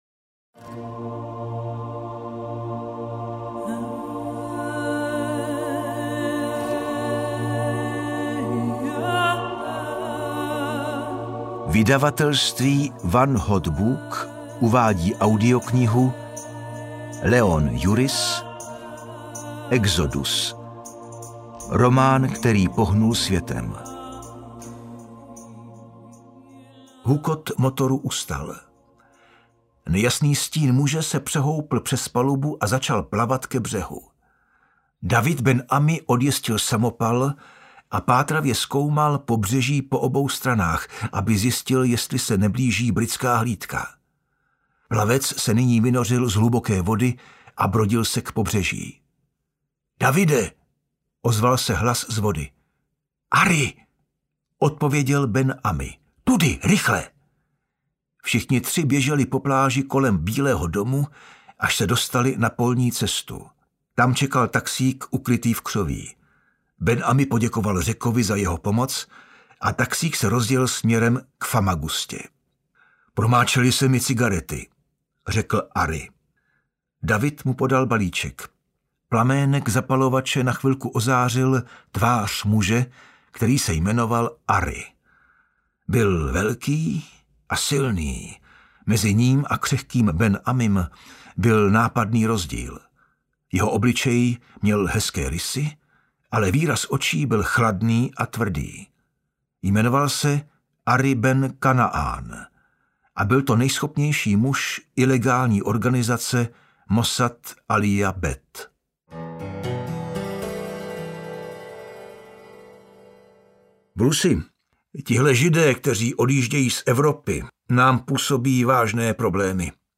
Audiokniha Exodus, kteru napsal Leon Uris.
Ukázka z knihy